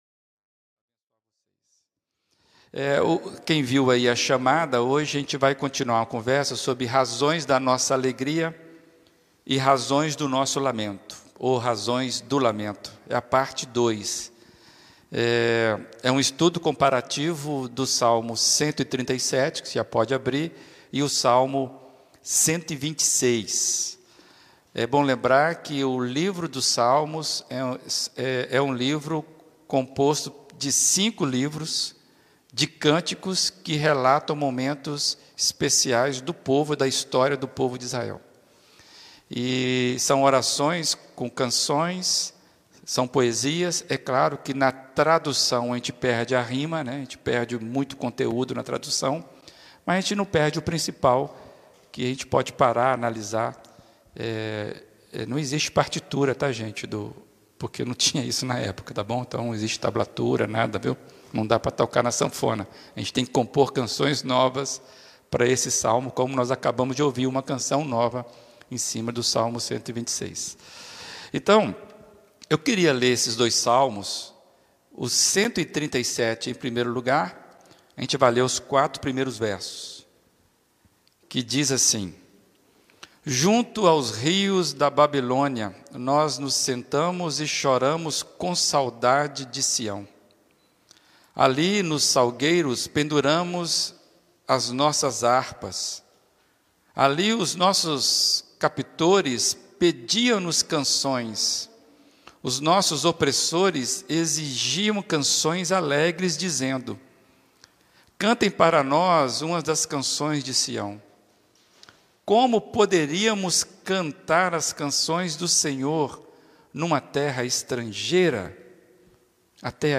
Mensagem apresentada